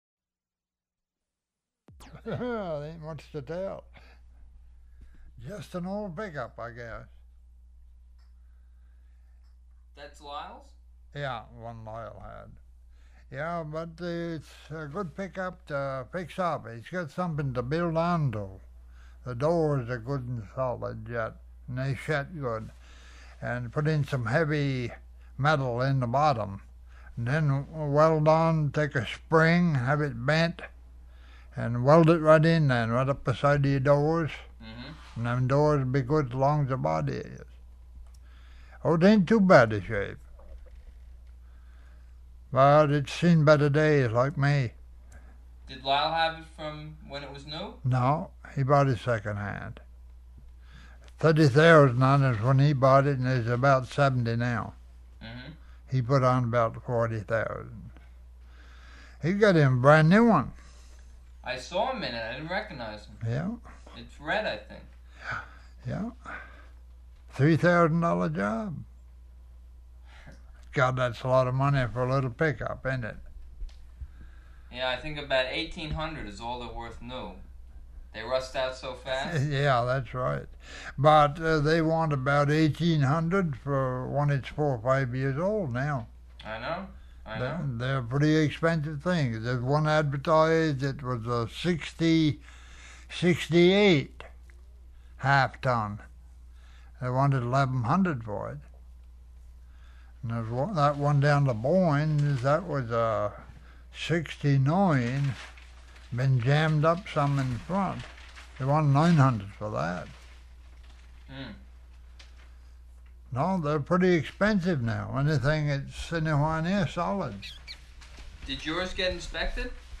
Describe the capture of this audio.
Format 1 sound tape reel (Scotch 3M 208 polyester) : analog ; 7 1/2 ips, full track, mono.